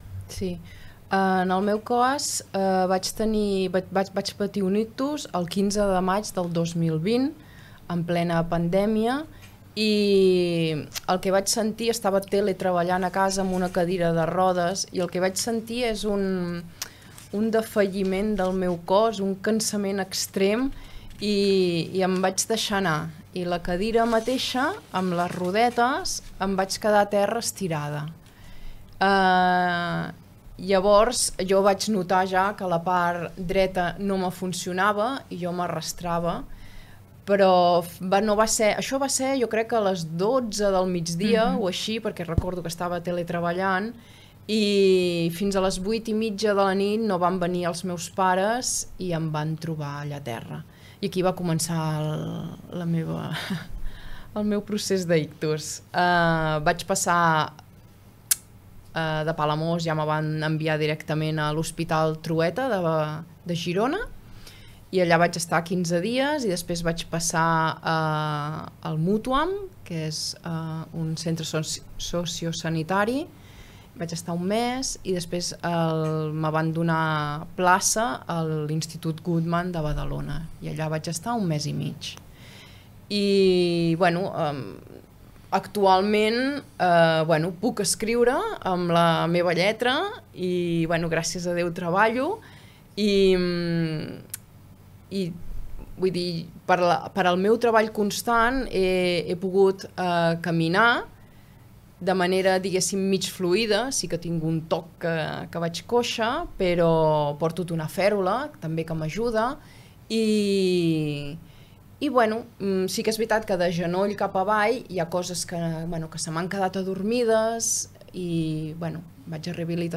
han compartit en una entrevista a Ràdio Capital els seus testimonis personals sobre què suposa patir un ictus i com es pot continuar vivint amb aquesta nova realitat.